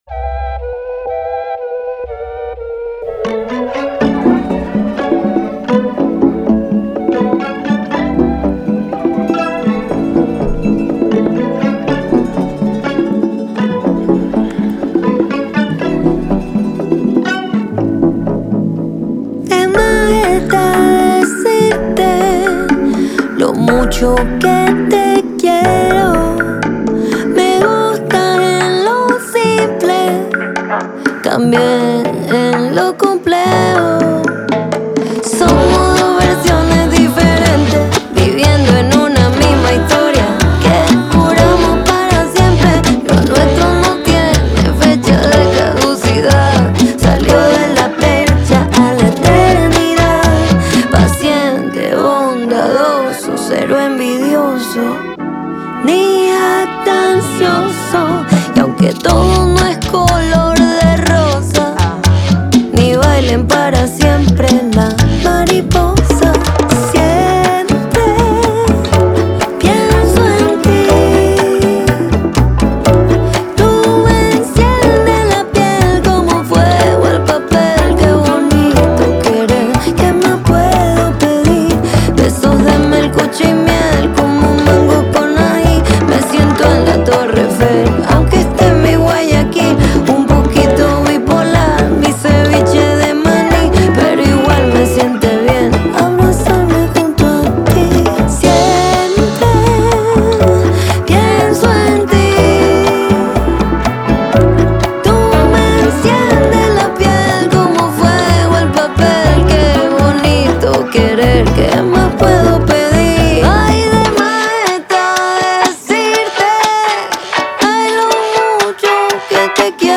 La cantautora ecuatoriana